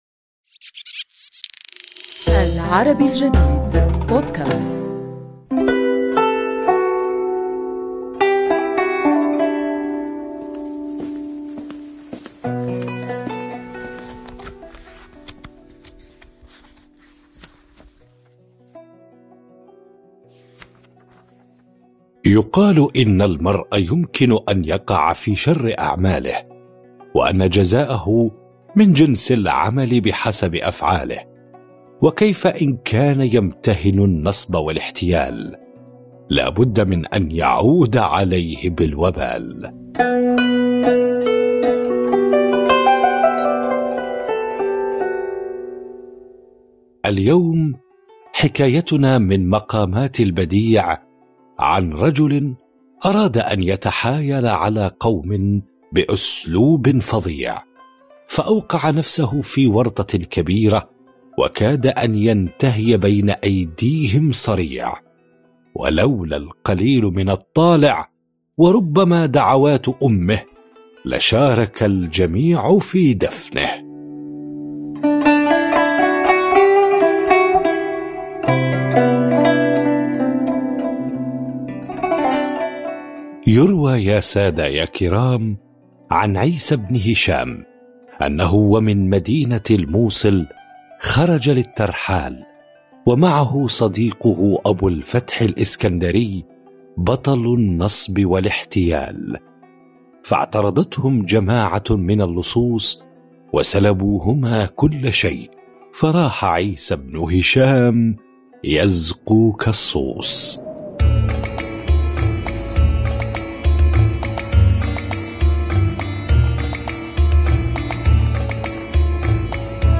نروي لكم اليوم في بودكاست "حكواتي" ، حكايةً عن رجل أراد أن يتحايل على قوم مستخدماً أسلوباً فظيعاً، فوقع في ورطة كبيرة، وكاد ينتهي بين أيديهم صريعاً.